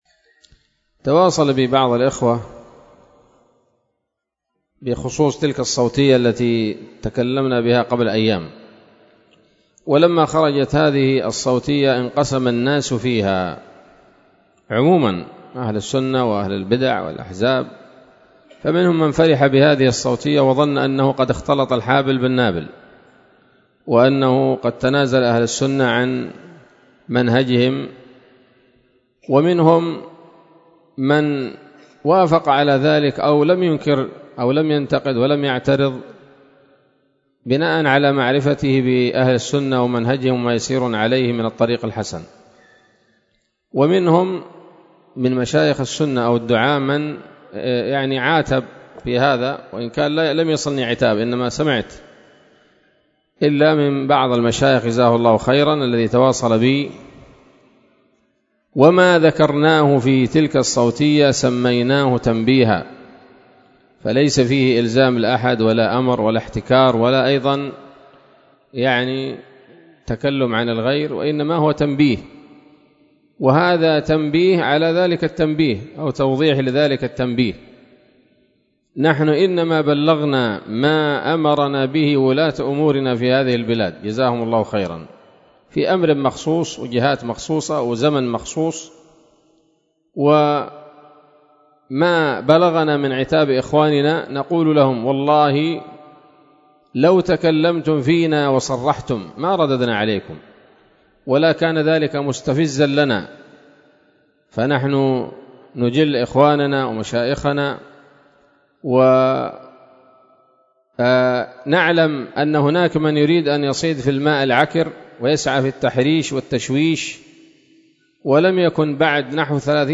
كلمة قيمة بعنوان: ((تنبيه على التنبيه )) ظهر الثلاثاء 2 جمادى الآخرة 1446هـ، بدار الحديث السلفية بصلاح الدين